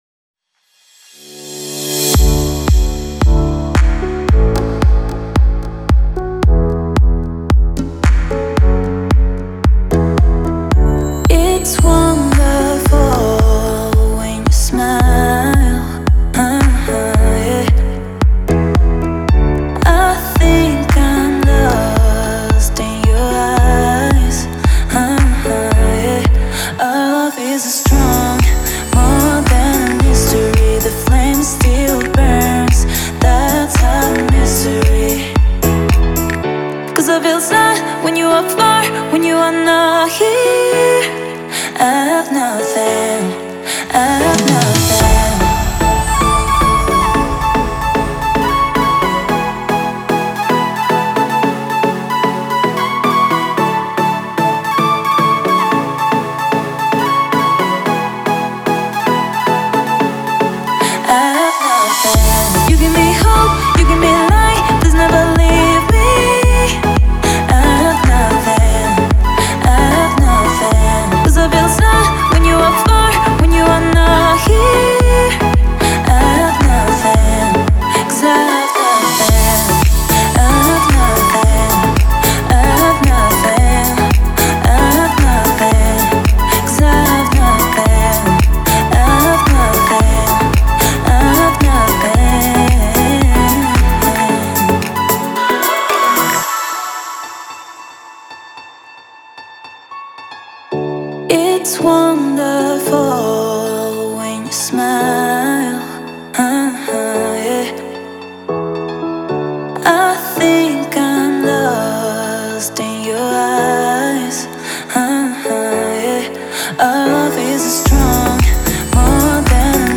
это мощная баллада в жанре поп